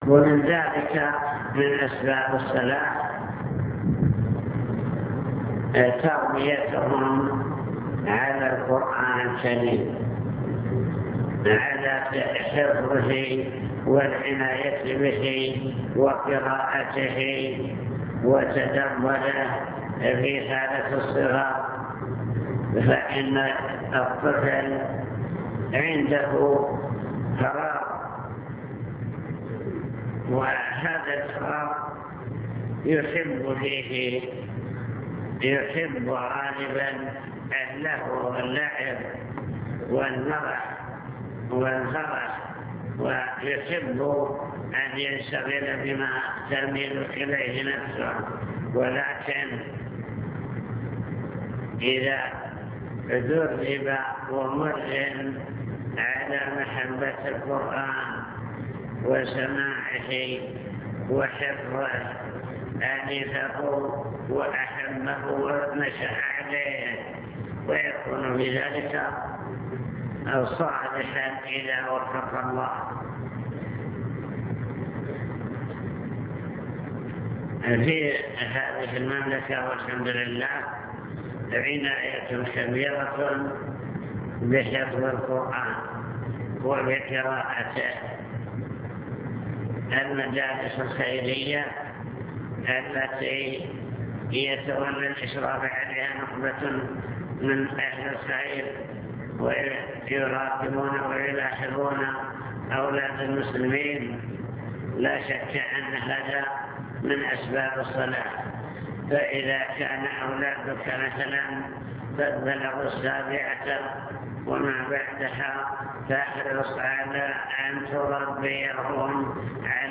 المكتبة الصوتية  تسجيلات - محاضرات ودروس  محاضرة بعنوان توجيهات للأسرة المسلمة طرق وأسباب صلاح الأبناء